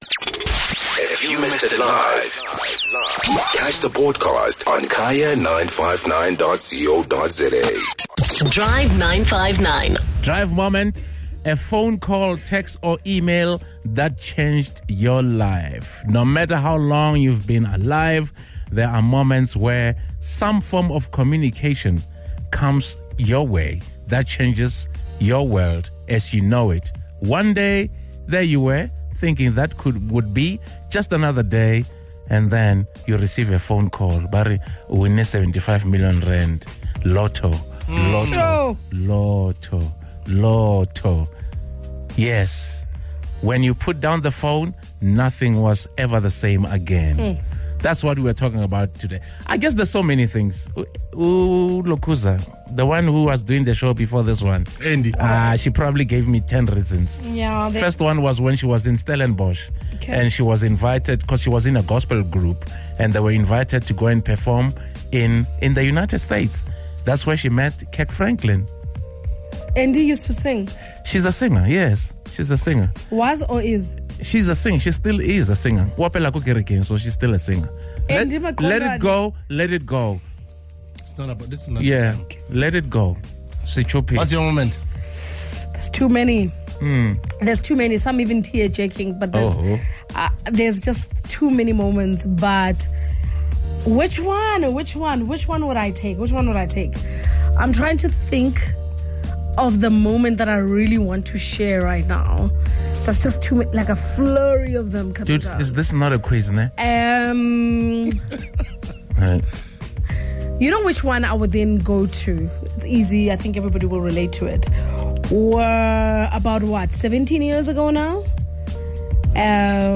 Our Drive 959 listeners had some touching stories to share.